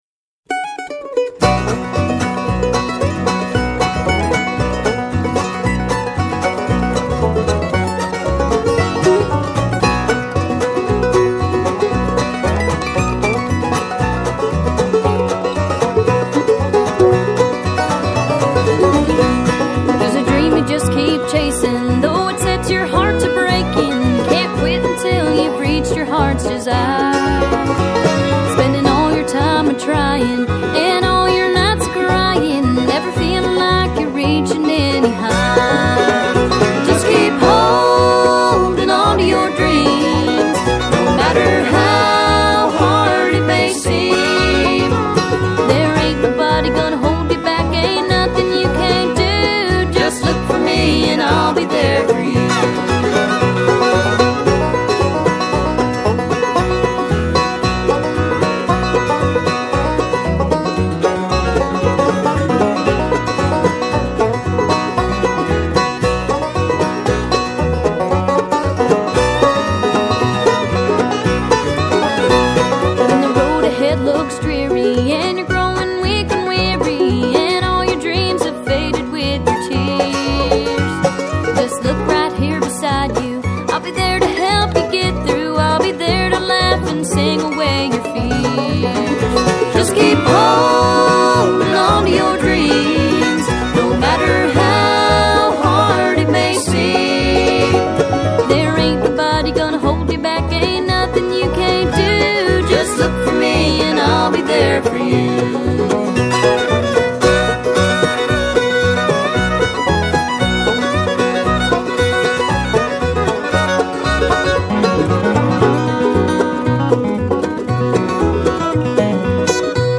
mandolin, harmony fiddle & vocals
bass
banjo
dobro
finger style guitar
Her voice fits both genres effortlessly and naturally.